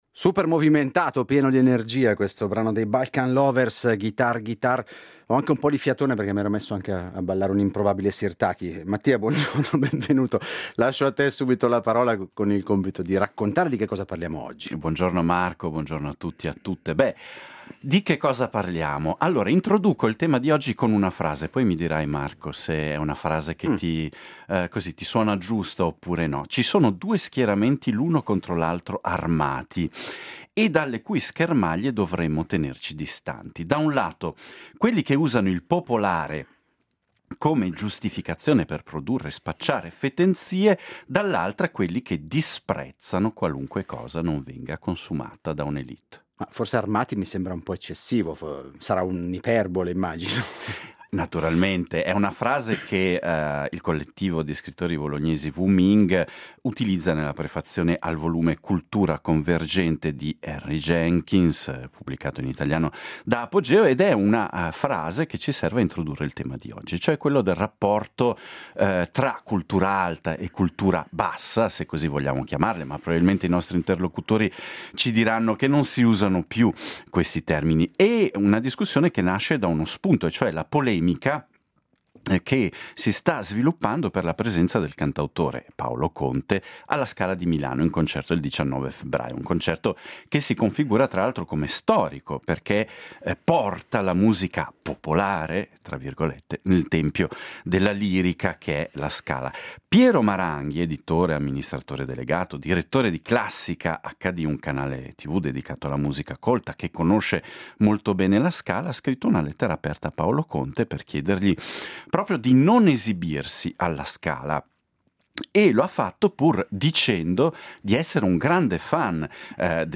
Lo spunto per il dibattito di oggi è la polemica nata dalla presenza del cantautore Paolo Conte alla Scala di Milano il 19 febbrai o, un concerto che si configura come storico, dal momento che porta la musica “popolare” nel tempio della lirica. Si ripropone quindi il grande dibattito tra cultura alta e cultura bassa, o cultura popolare, sulla loro ibridazione e sui luoghi adatti ad ospitare manifestazioni culturali di diversa estrazione.